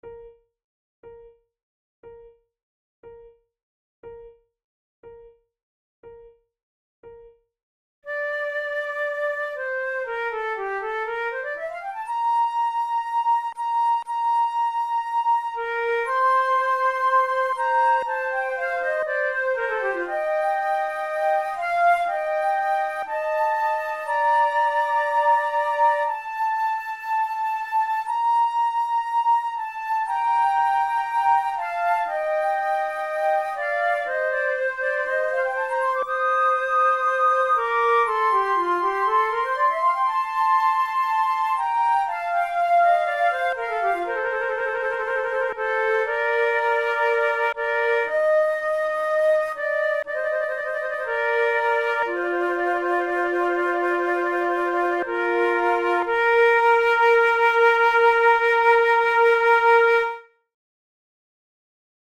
Sheet Music MIDI MP3 Accompaniment: MIDI